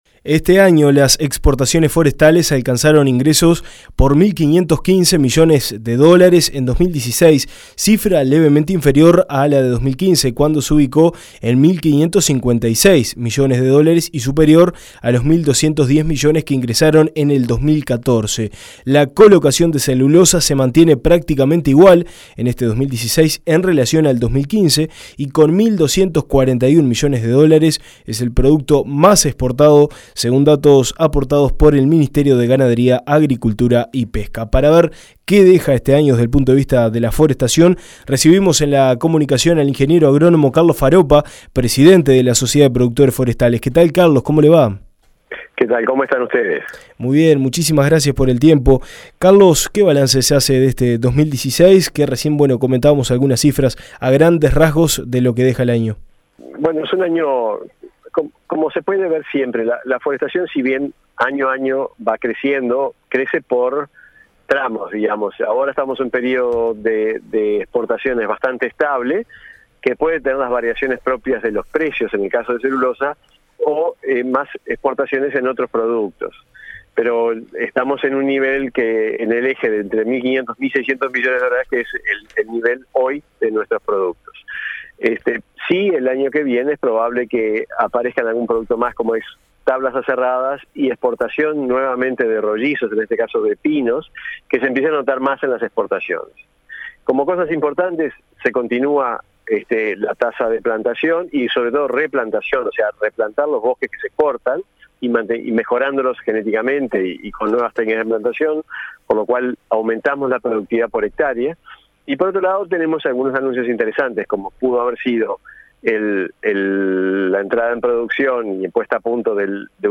En entrevista con Dinámica Rural, el Ing.